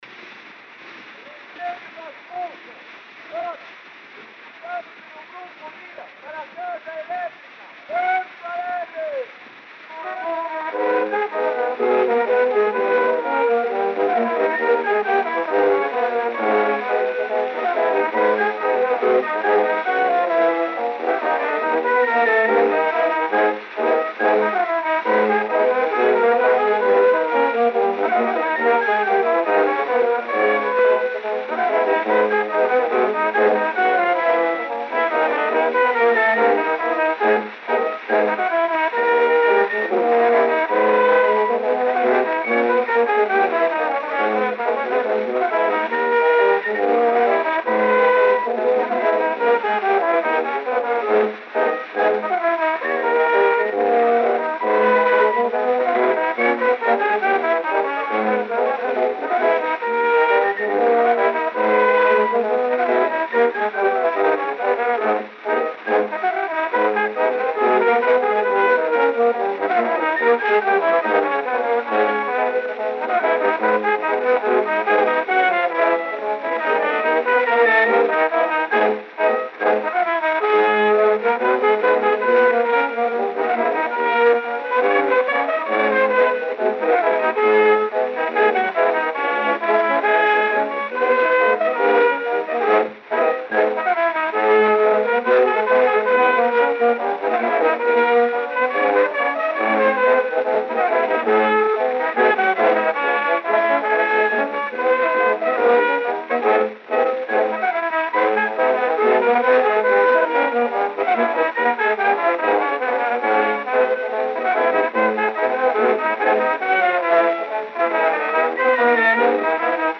O gênero musical foi descrito como "Schottisch".
Gênero: Schottisch.